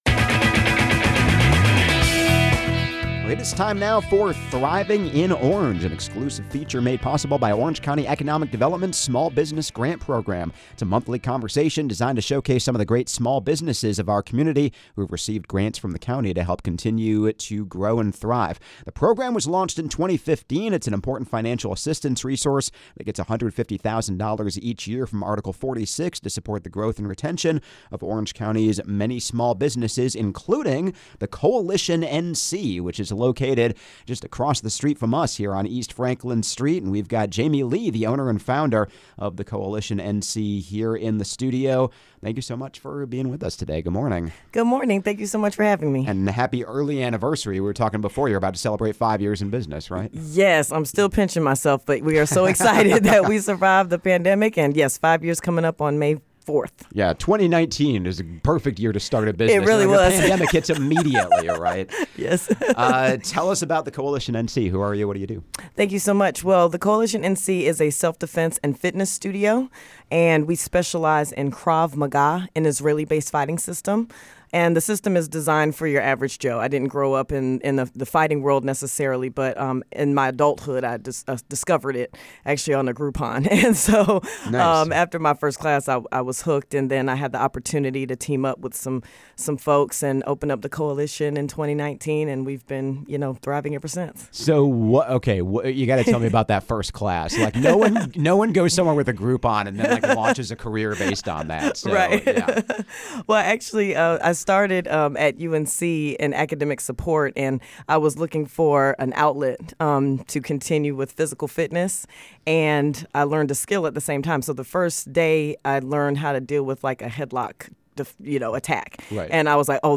You can find more conversations like this in the “Thriving in Orange” archive on Chapelboro, and each month in a special segment airing on 97.9 The Hill!